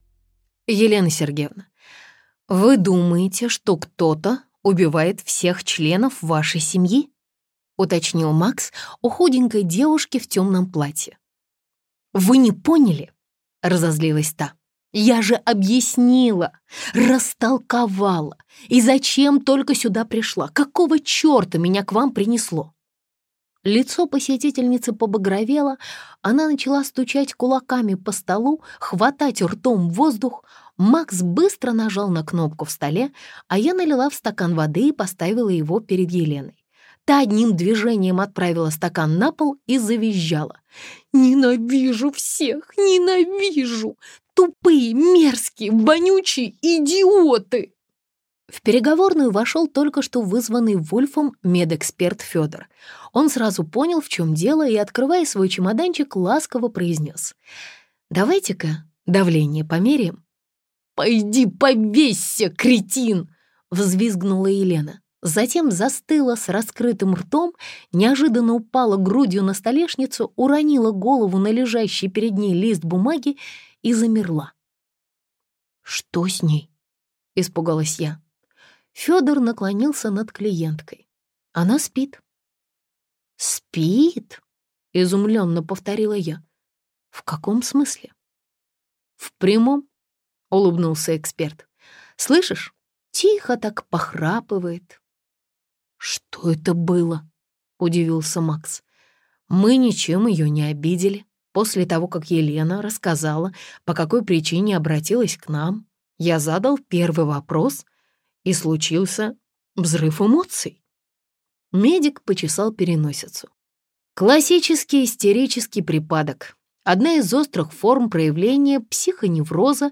Аудиокнига Гороскоп птицы Феникс - купить, скачать и слушать онлайн | КнигоПоиск